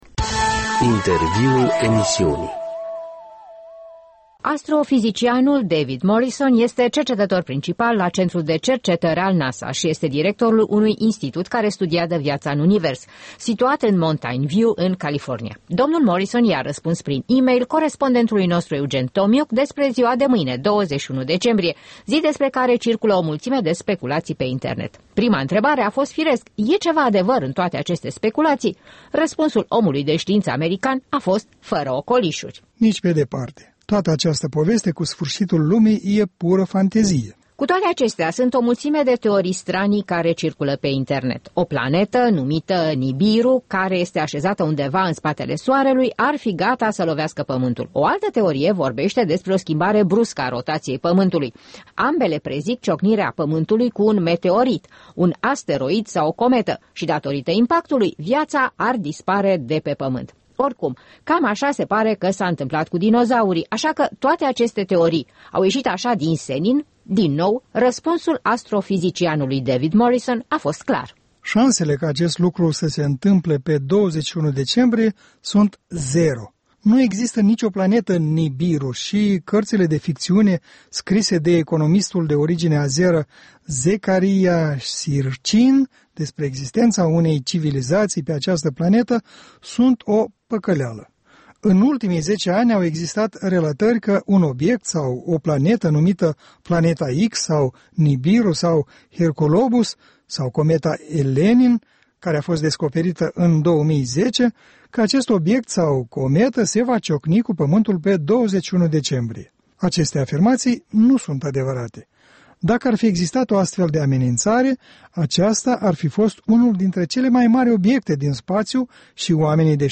Un interviu cu astrofizicianul David Morrison despre... „sfîrșitul lumii”